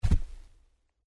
在积雪里行走的脚步声左声道2－YS070525.mp3
通用动作/01人物/01移动状态/02雪地/在积雪里行走的脚步声左声道2－YS070525.mp3